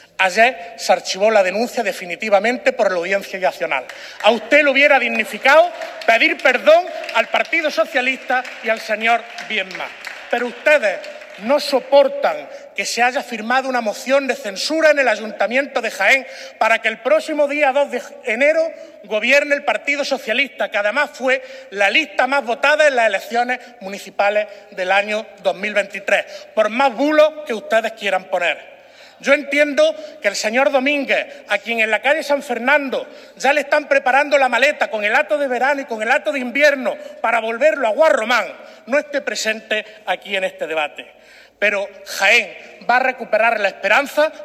Torres hizo estas declaraciones durante su intervención en el pleno del Parlamento andaluz, donde afeó al portavoz del PP-A, Toni Martín, que el año pasado pidiera apartar a Jacinto Viedma de sus cargos hasta que se aclarara todo lo relativo a una denuncia que dos concejales del PP de Jaén habían presentado.
Cortes de sonido